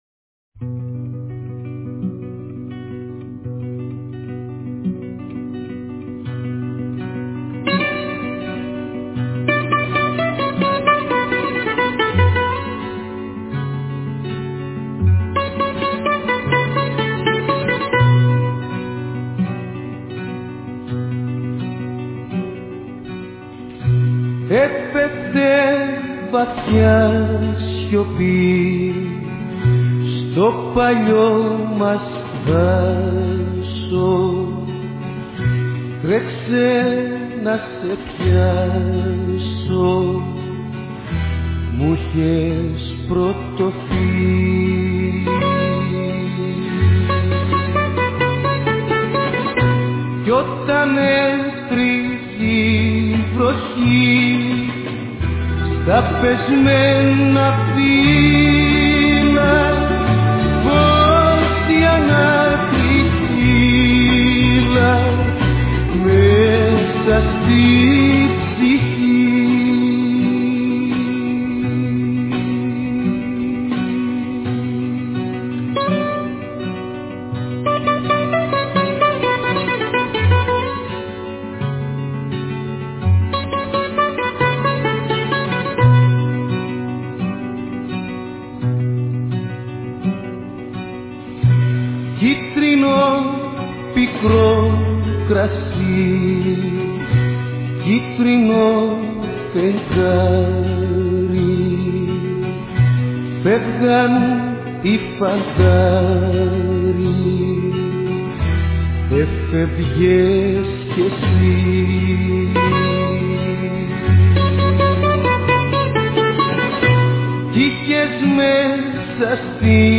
Música Grega